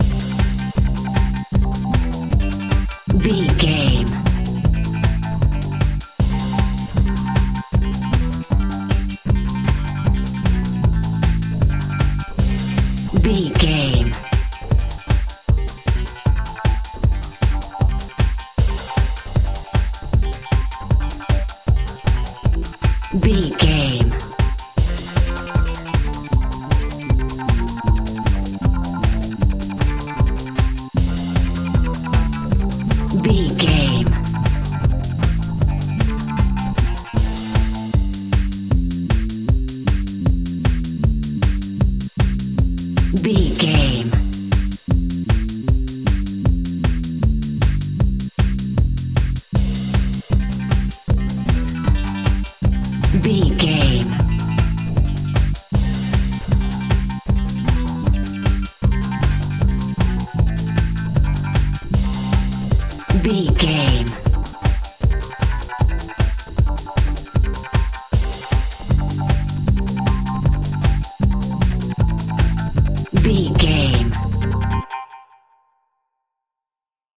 Fast Paced House.
Ionian/Major
aggressive
dark
driving
energetic
intense
futuristic
drum machine
synthesiser
bass guitar
acid house music